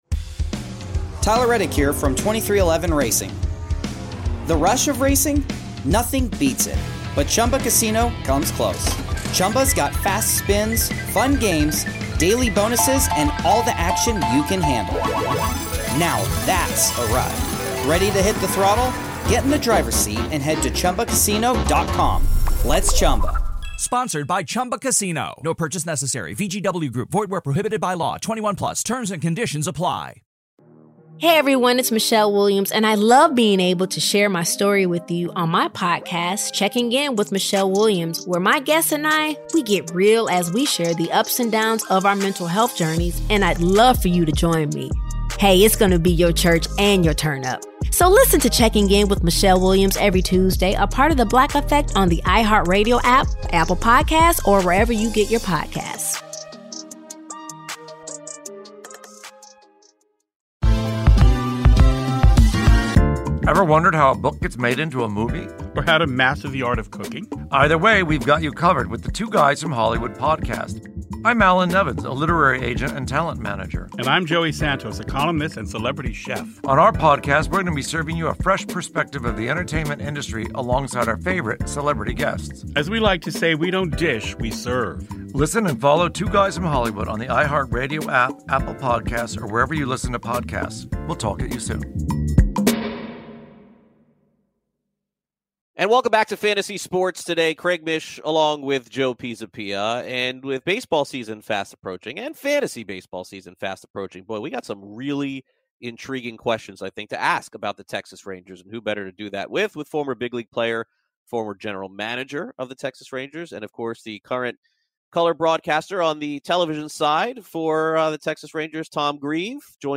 Texas Rangers Broadcaster Tom Grieve joins the show to talk about the Rangers new ballpark, the fantasy play of Rougned Odor, and how the Astros need to apologize to MLB.